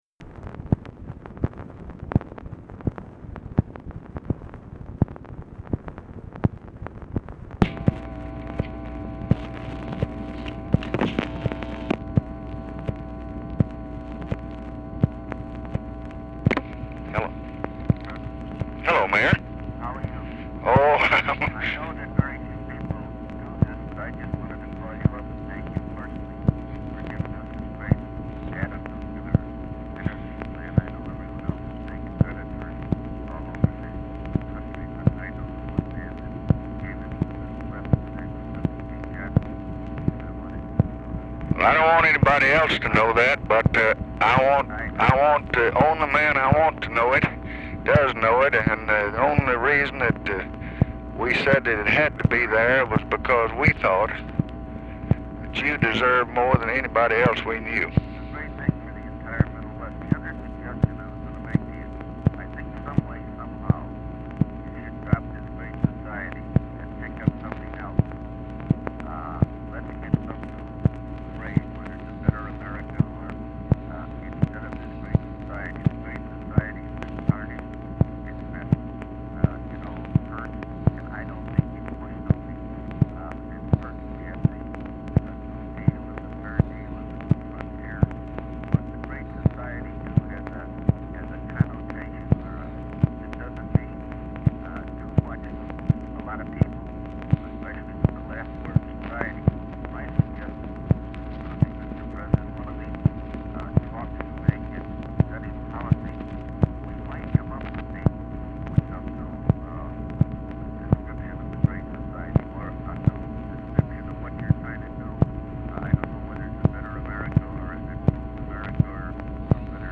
Telephone conversation # 11145, sound recording, LBJ and RICHARD DALEY, 12/17/1966, 9:48AM | Discover LBJ
Format Dictation belt
Location Of Speaker 1 LBJ Ranch, near Stonewall, Texas